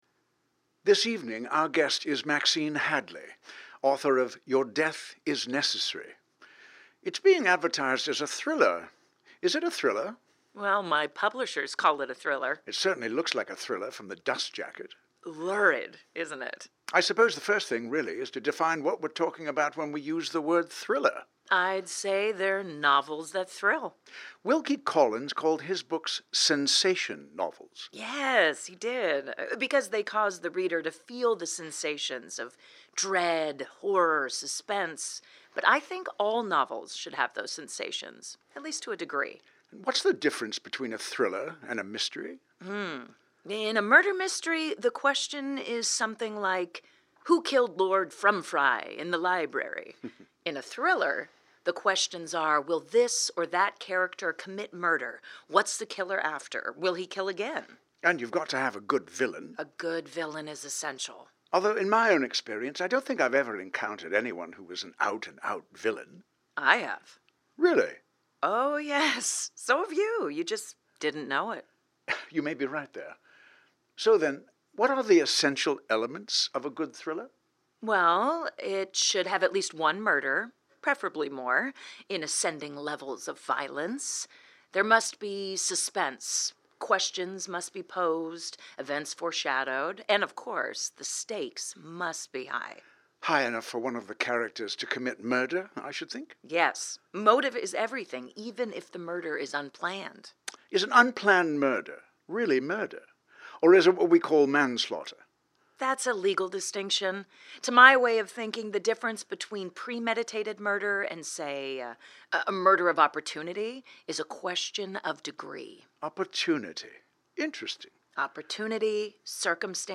BBC-Radio-Interview-Rehearsal-Cut-1-9-25-1.mp3